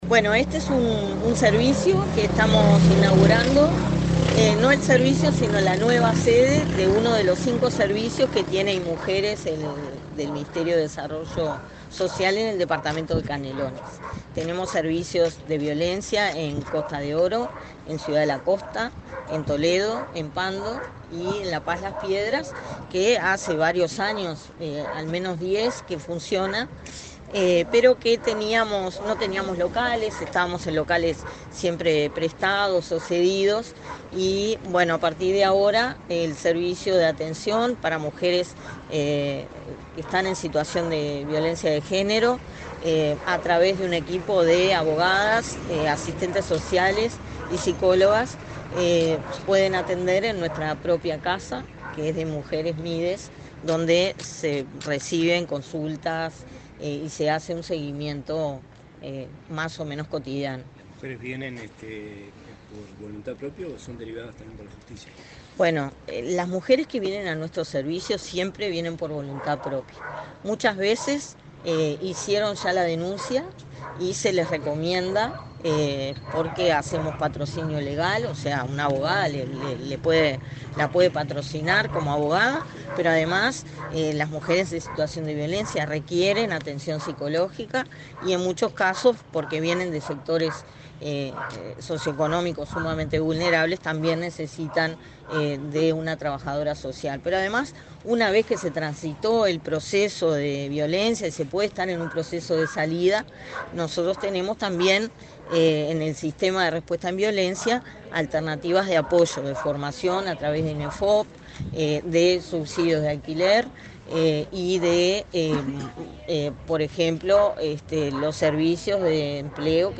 Declaraciones de la directora del Inmujeres, Mónica Bottero
Declaraciones de la directora del Inmujeres, Mónica Bottero 09/07/2024 Compartir Facebook X Copiar enlace WhatsApp LinkedIn El directora del Instituto Nacional de las Mujeres (Inmujeres), Mónica Bottero, dialogó con la prensa en Las Piedras, Canelones, durante la inauguración de un local de atención a mujeres expuestas a violencia.